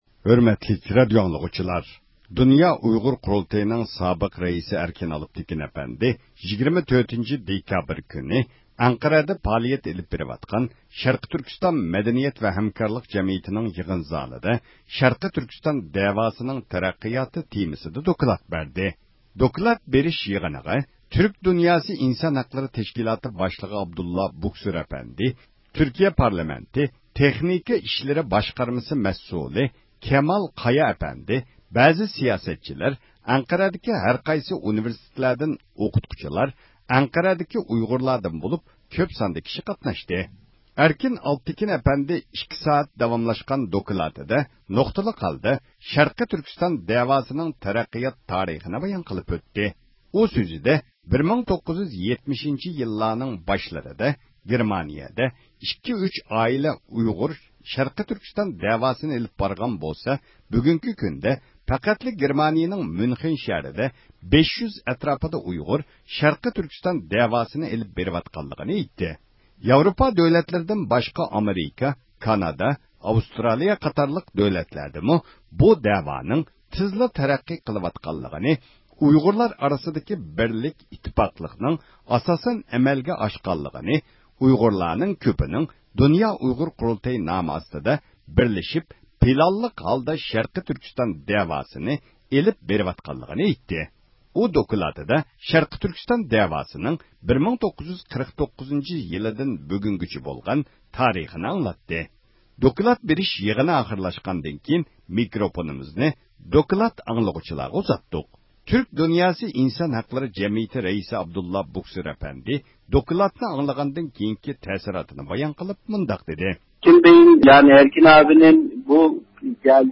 دوكلات بېرىش يىغىنى ئاخىرلاشقاندىن كېيىن مىكروفونىمىزنى دوكلات ئاڭلىغۇچىلارغا ئۇزاتتۇق.